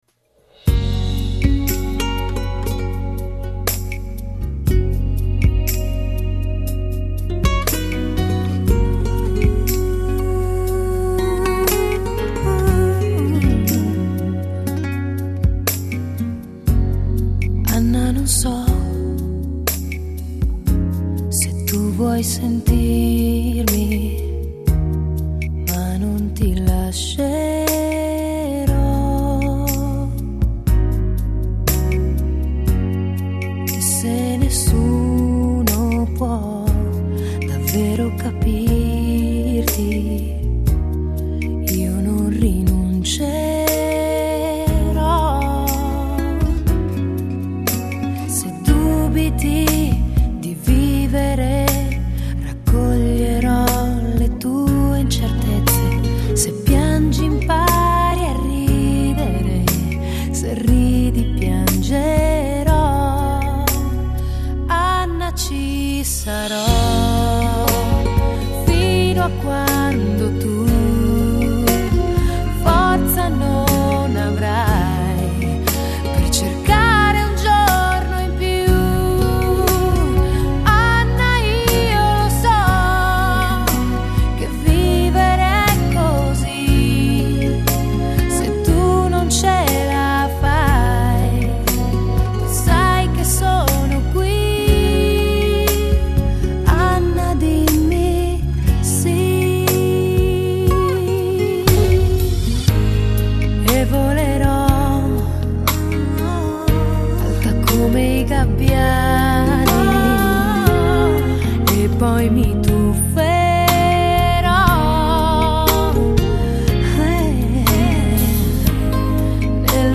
Качество на высоте. Красивый медляк на итальянском.
+ замечательный голос.
Это не дынц-дынц.